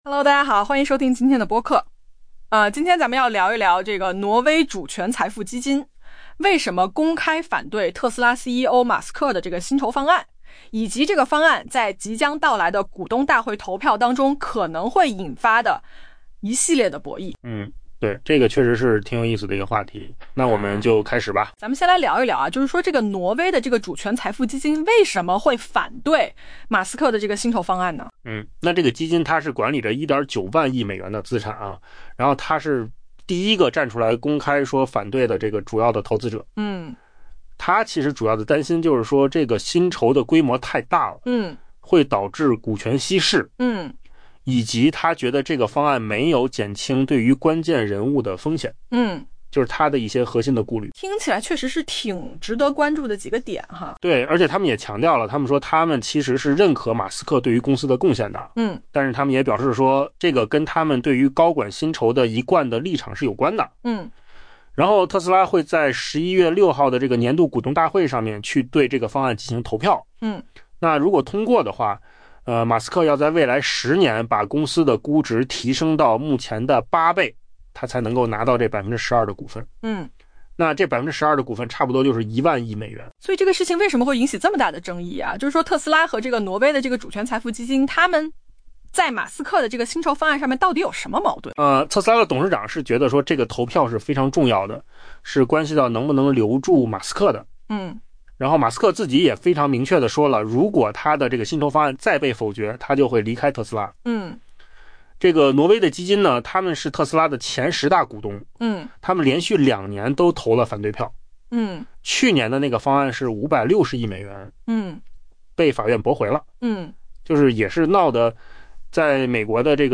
AI 播客：换个方式听新闻 下载 mp3 音频由扣子空间生成 挪威规模达 1.9 万亿美元的主权财富基金，成为首家公开表态的主要投资者，明确反对特斯拉 （TSLA） 首席执行官埃隆·马斯克 （Elon Musk） 新提出的 1 万亿美元薪酬方案。